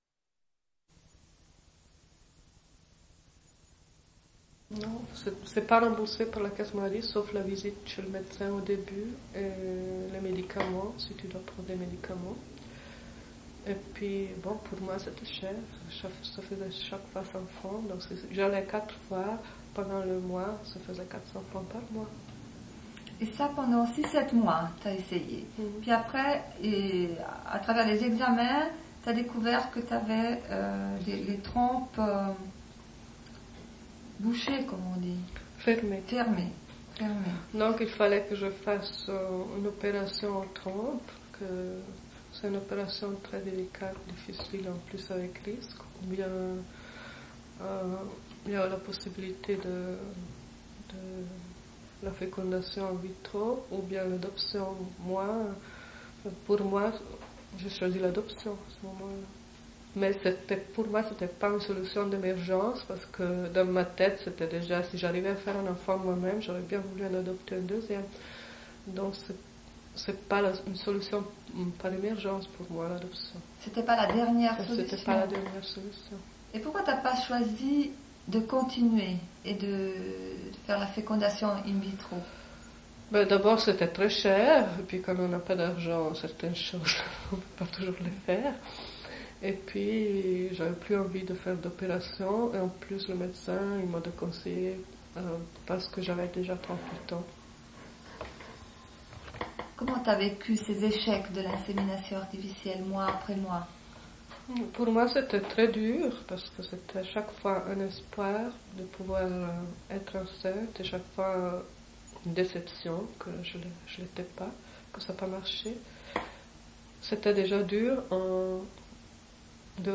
Une cassette audio